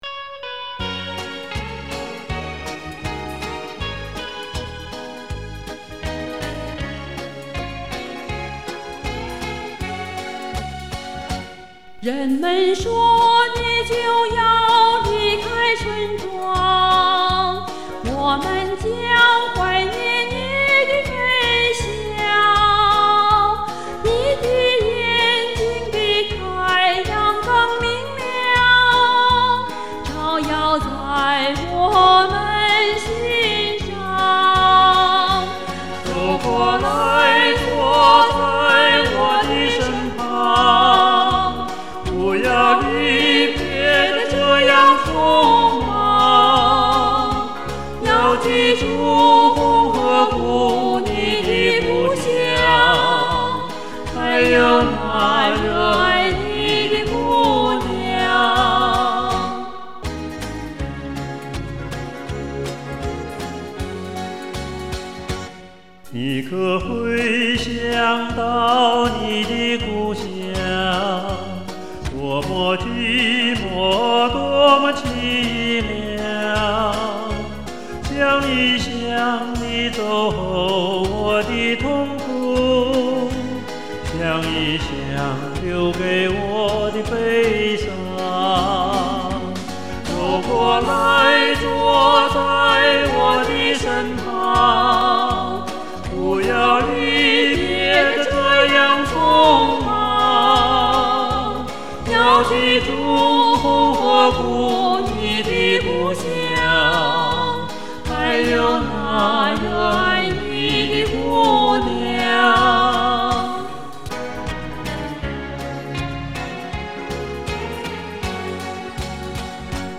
(二重唱) ------- 红河谷
女声美, 男声悠, 声部准